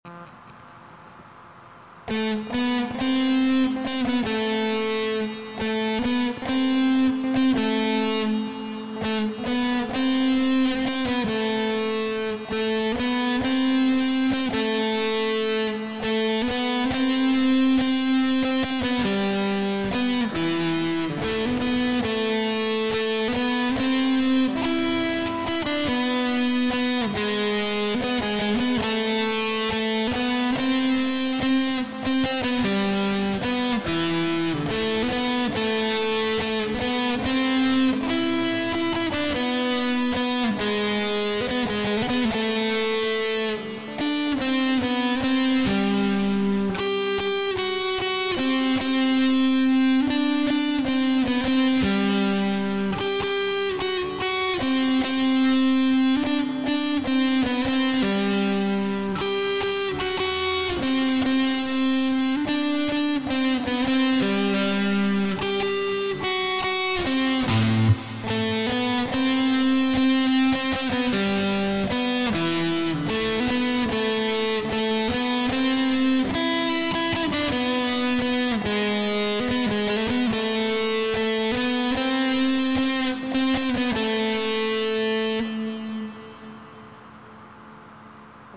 Музыка: с помощью моей палко